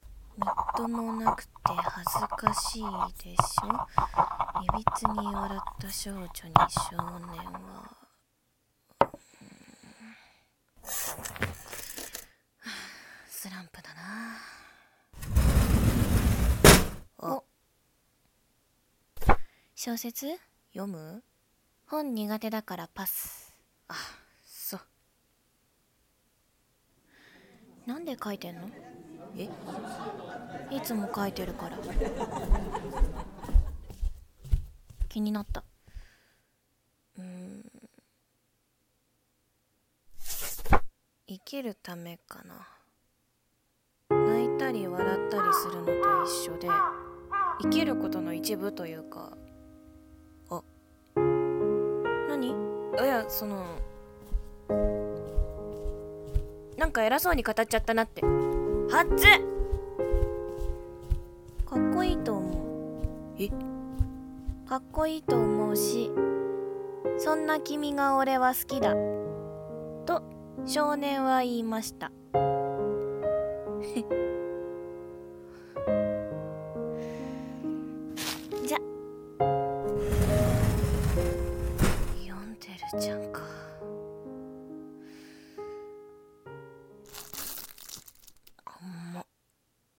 二人声劇